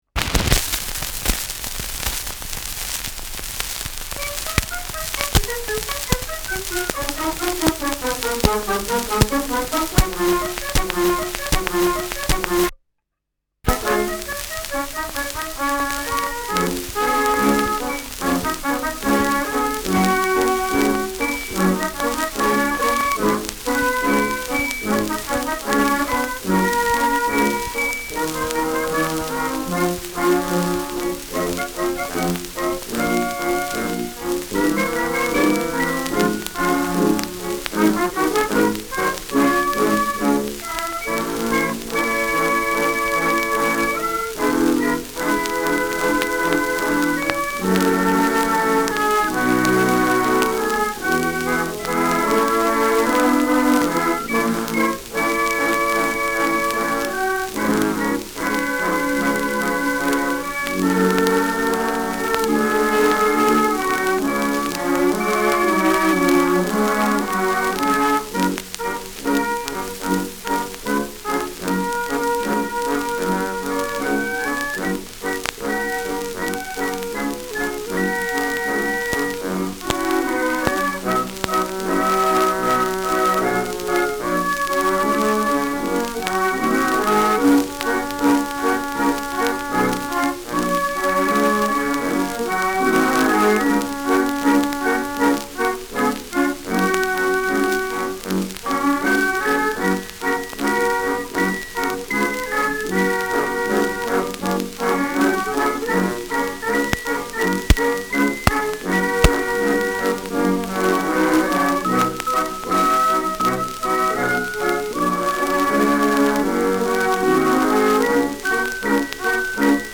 Schellackplatte
Operettenmelodie* FVS-00011